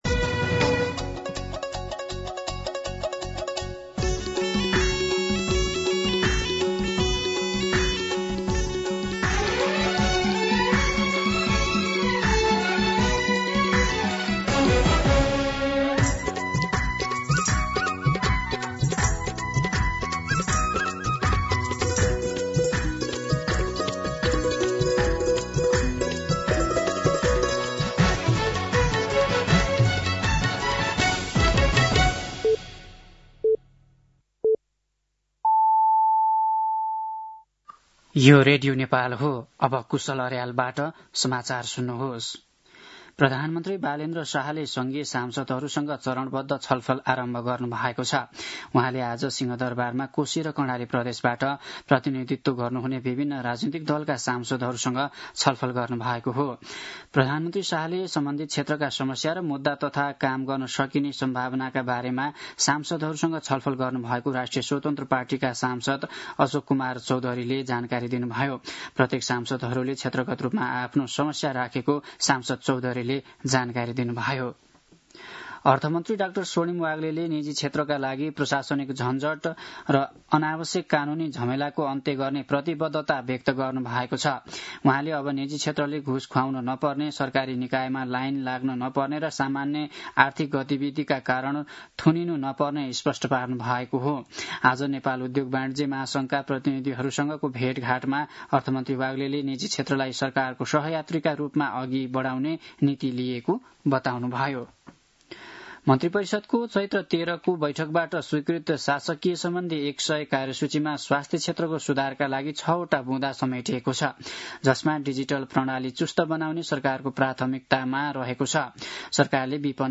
दिउँसो ४ बजेको नेपाली समाचार : १६ चैत , २०८२
4-pm-Day-News-12-16.mp3